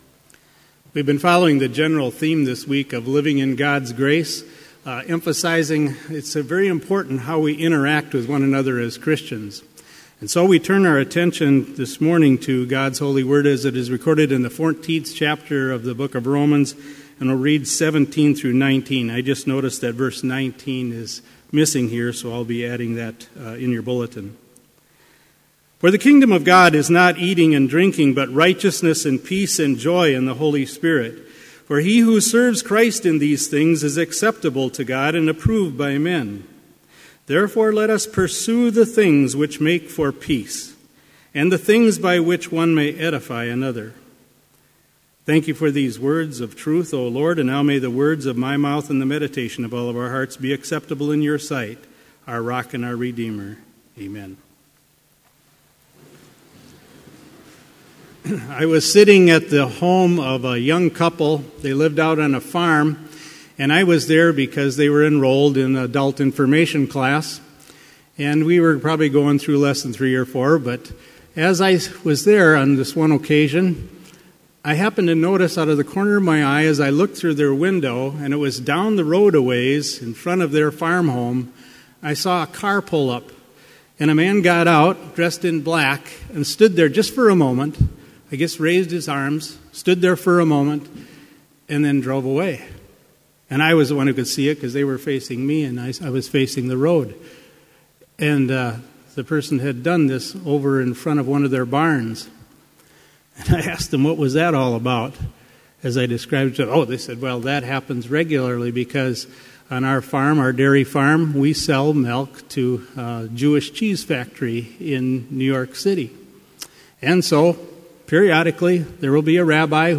Complete service audio for Chapel - September 21, 2016